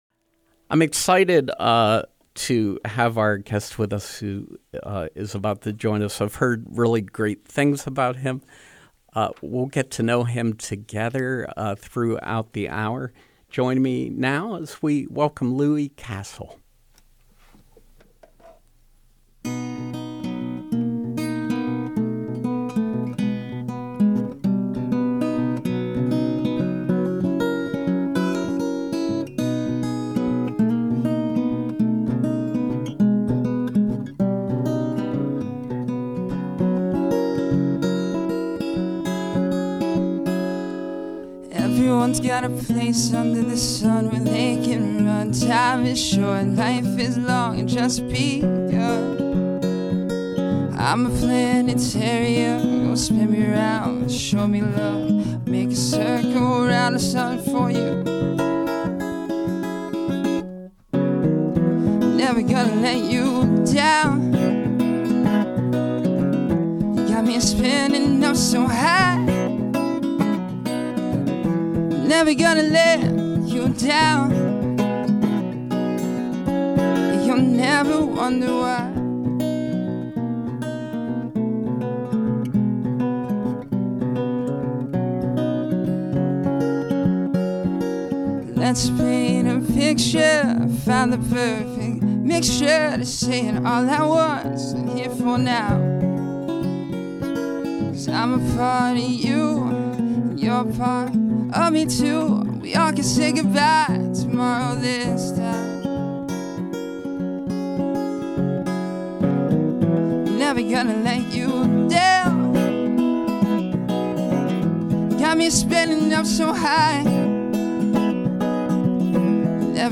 Music with singer, songwriter, and busker